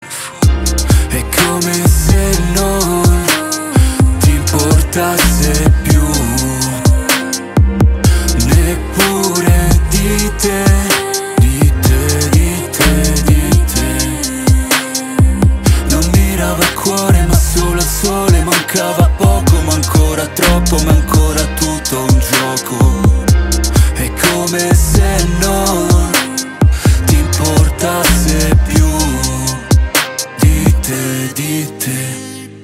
Categoria Hip Hop